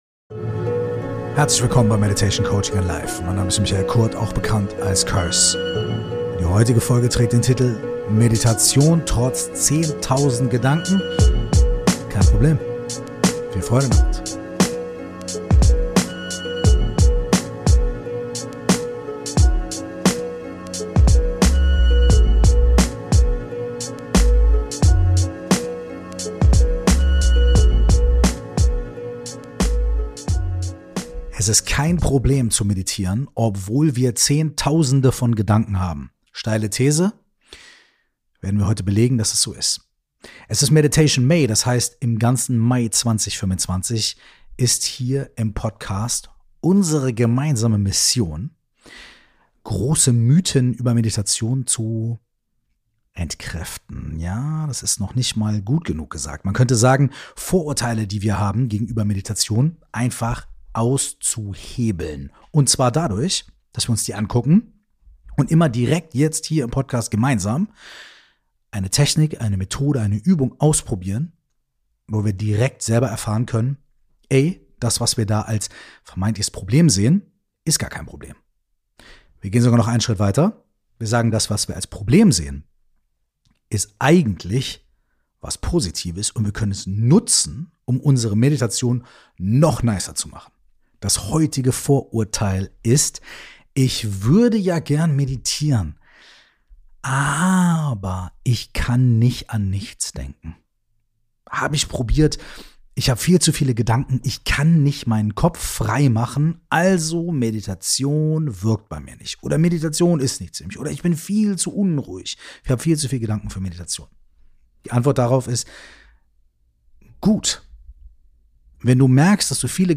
Diesen Monat wirft Curse jede Woche ein Vorurteil über das Meditieren über Bord - und stellt eine geführte Praxis vor, dies das „Problem“ in‘s positive verwandelt.